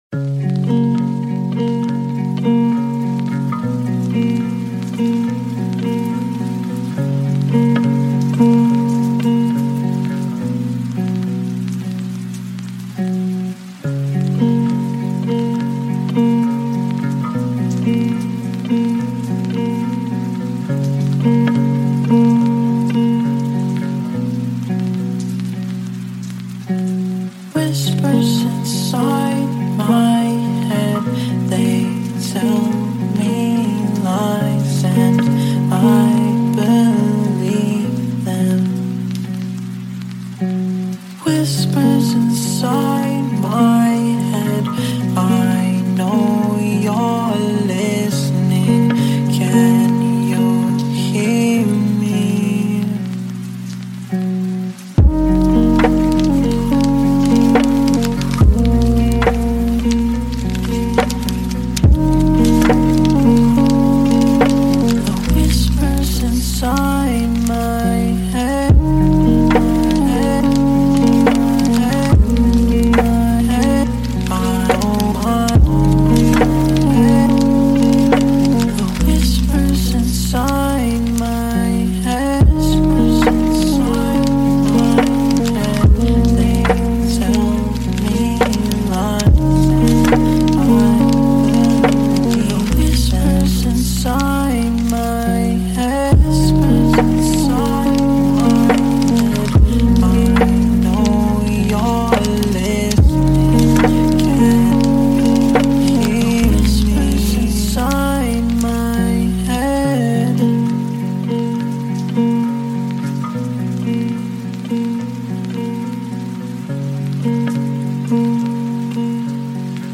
Session d’Étude : Feu Doux 1h